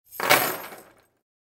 Звуки цепи
Бросили цепь на деревянную поверхность